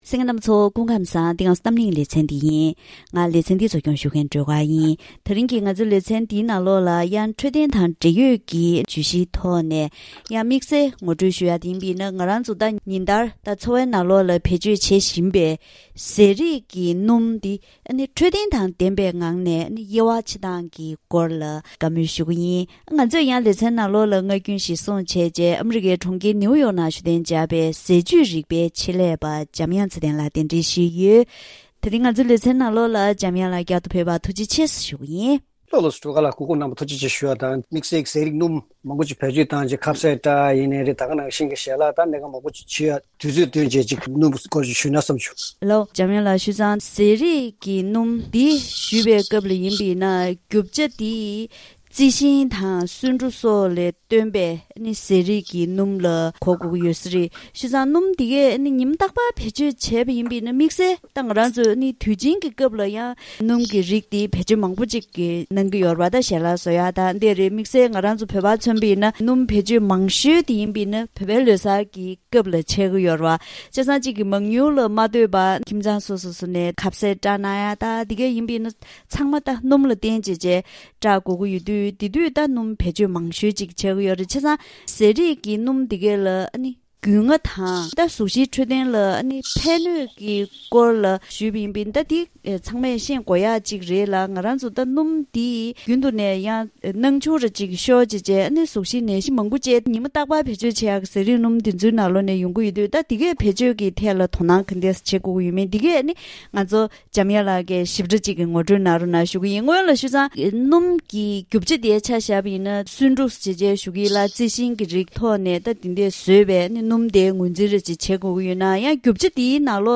བེད་སྤྱོད་བྱེད་བཞིན་པའི་ཟས་རིགས་ཀྱི་སྣུམ་འཕྲོད་བསྟེན་དང་ལྡན་པའི་ངང་གདམ་ཀ་བྱེད་སྟངས་སྐོར་ལ་ཟས་བཅུད་རིག་པའི་ཆེད་ལས་པ་དང་ལྷན་དུ་བཀའ་མོལ་ཞུས་པ་ཞིག་གསན་རོགས་གནང་།